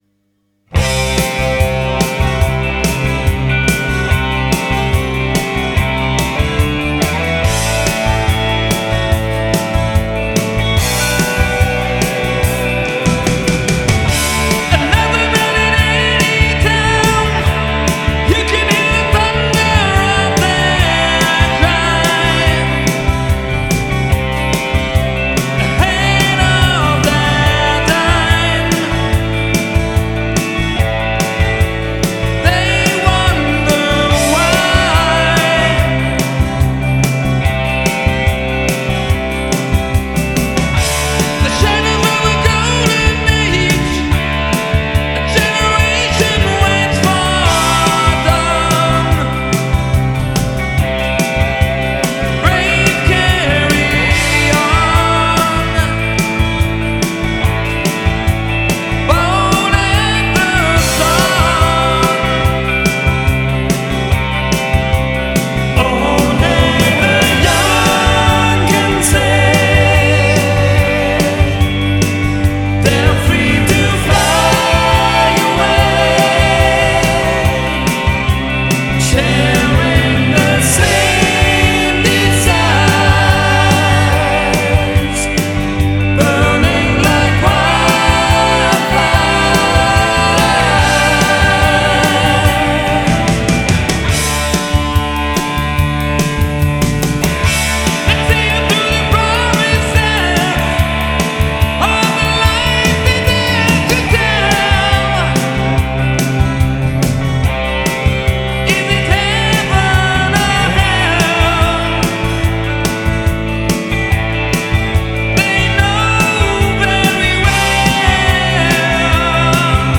(Blockhouse Studio Version)